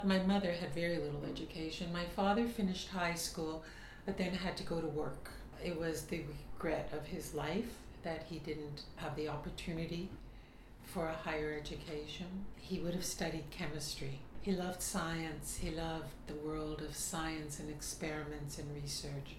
In meeting with me she prefaced the conversation (and the recording I took) with the following consideration: her vocal tract was degraded by a health issue, and is acoustically different than what she grew up with.